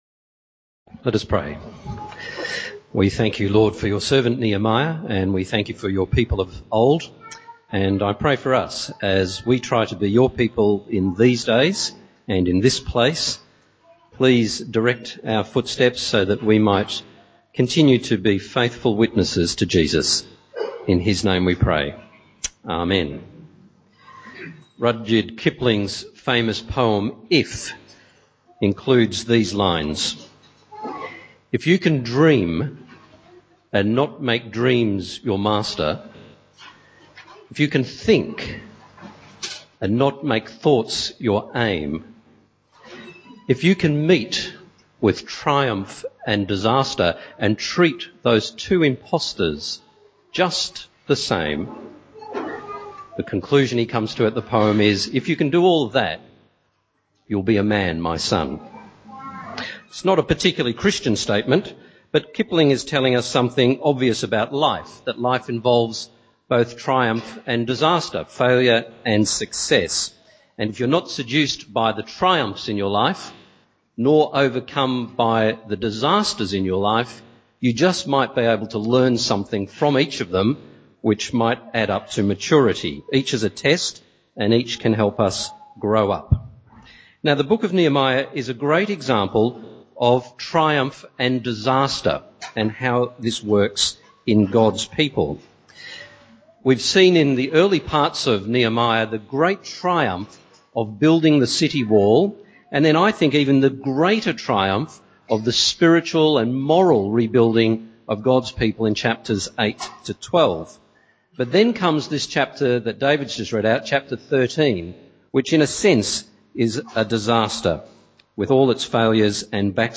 Bible Passage